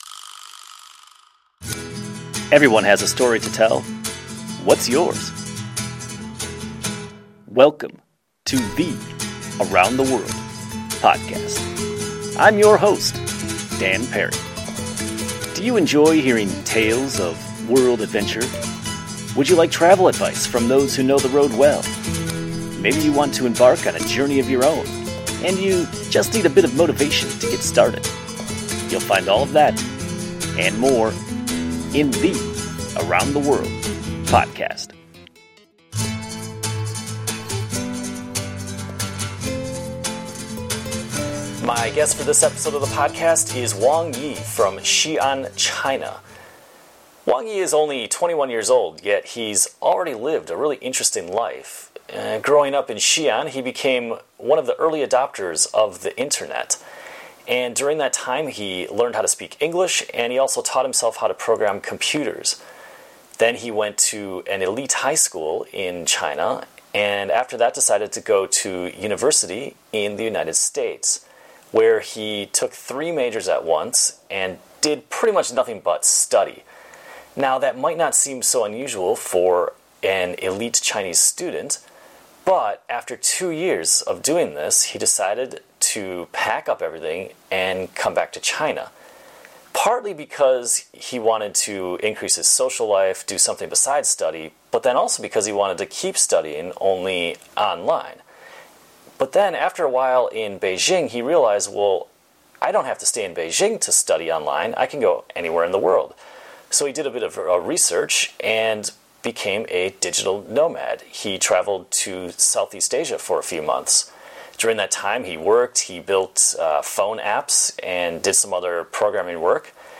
We sat on my balcony in Beijing to discuss his life story and his future plans.